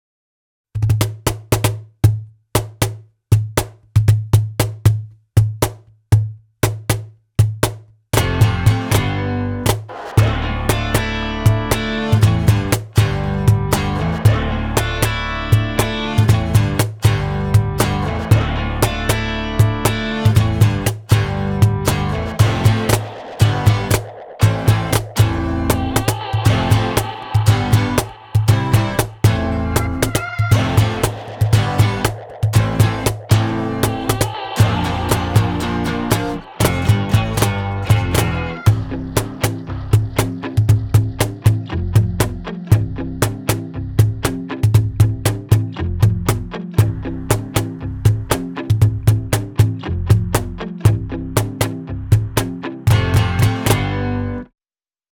MEINL Percussion Jam Series Snare Cajon 50 - Heart Ash (JC50HA)
The MEINL Jam Cajon with fixed internal snares is perfect for playing on the go.